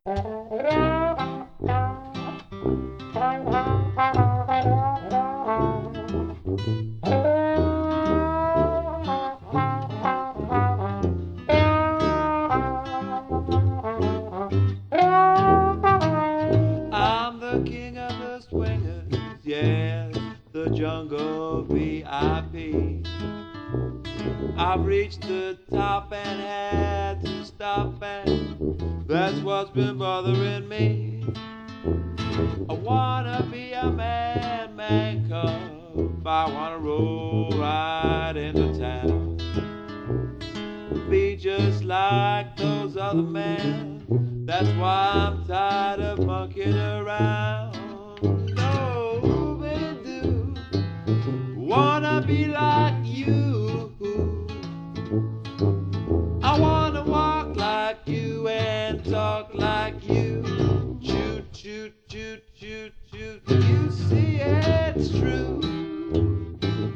• Versatile, genre-hopping jazz ensemble
• Traditional jazz but with a modern twist
• Inspired by '20s/'30s New Orleans music